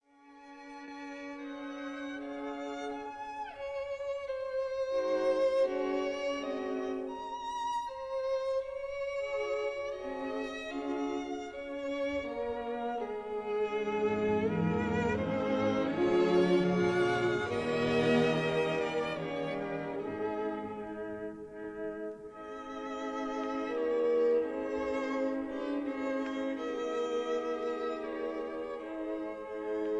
violins
violas
cellos